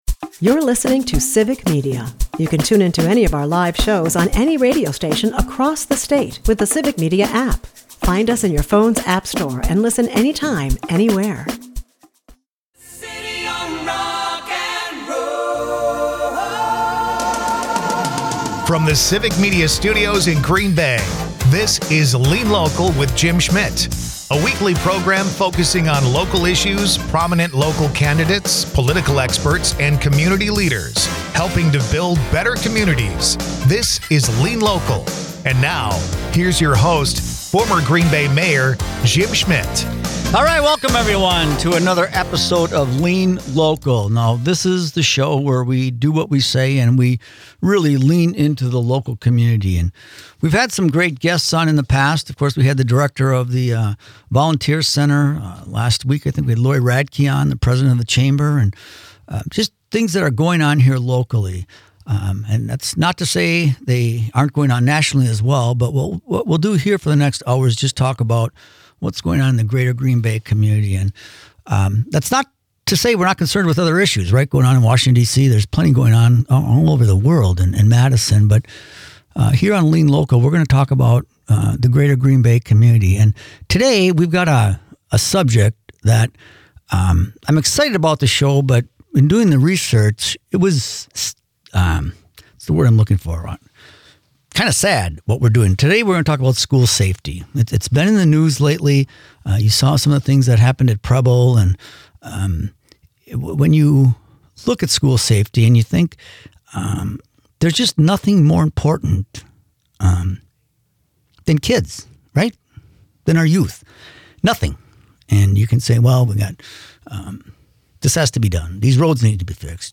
Former Green Bay Mayor Jim Schmitt welcomes a conversations with Students, Parents, Police, and School Board Members to talk School Safety.